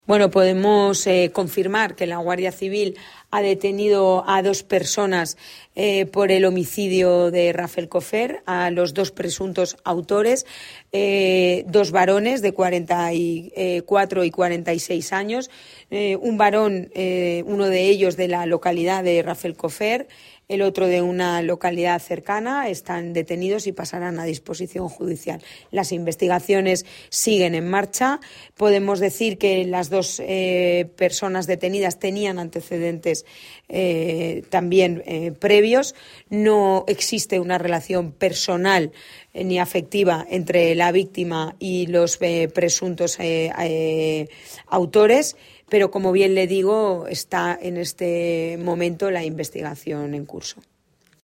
Delegada del Gobierno de España en la CV. Pilar Bernabé (audio)